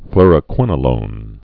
(flrə-kwĭnə-lōn, flôr-)